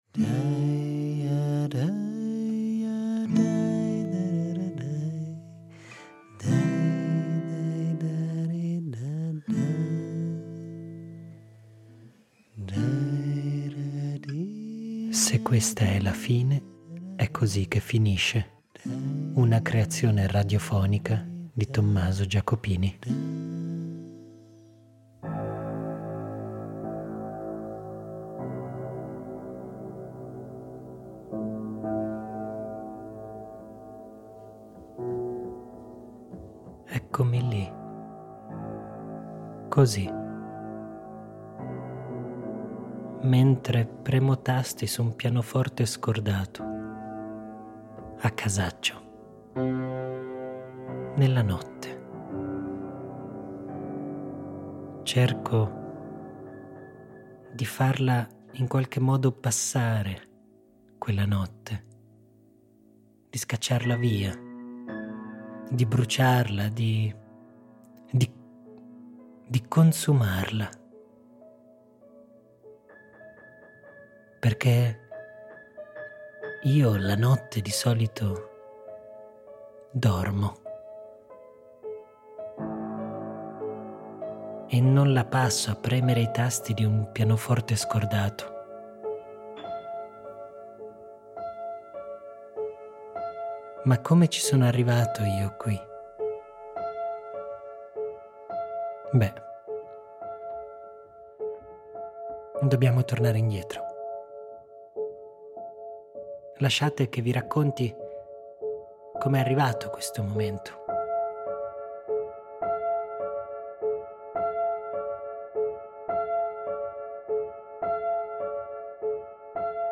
Di suoni di foche, di spari, di foreste piuvali.
E io scelgo di raccontarla così, un po’ come mi riesce, in una notte passata ai microfoni, cantando, gridando, ma soprattutto, ringraziando.